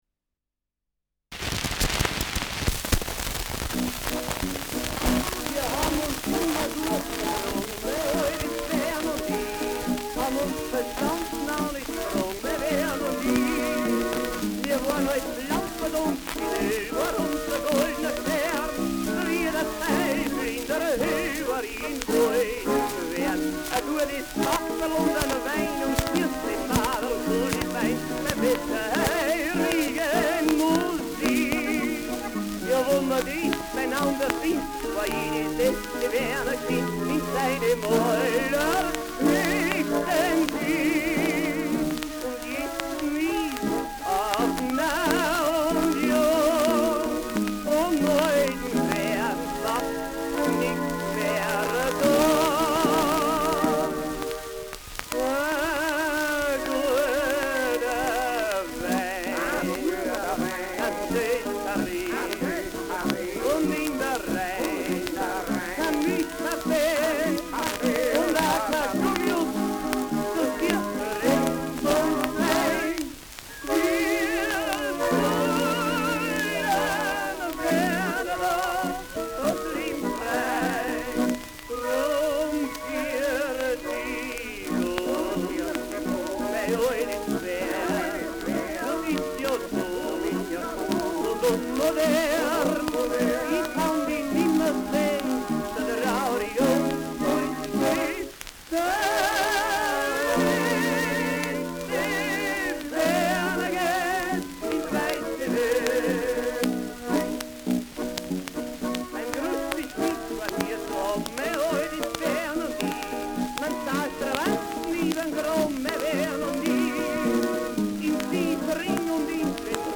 Schellackplatte
Tenor m. Chor : Schrammelmusik
[Wien] (Aufnahmeort)